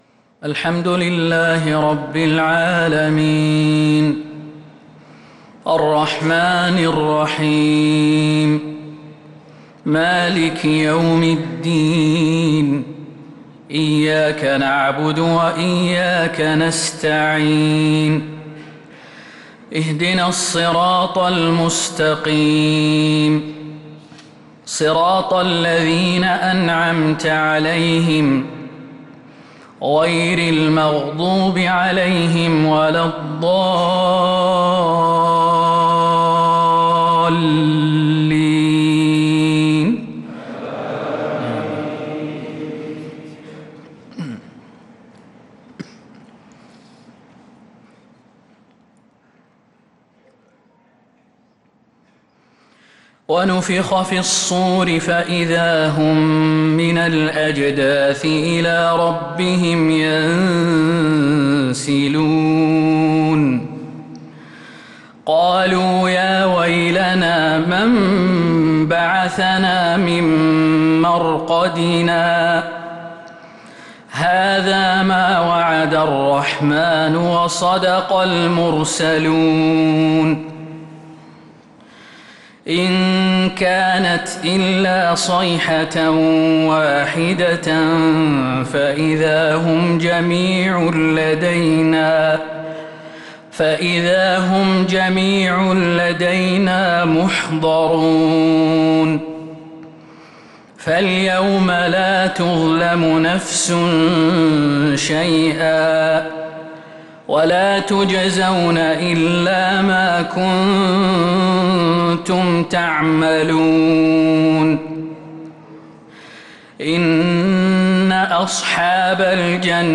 عشاء السبت 4-7-1446هـ من سورة يس 51-67 | isha prayer from Surat Ya Sin 4-1-2025 > 1446 🕌 > الفروض - تلاوات الحرمين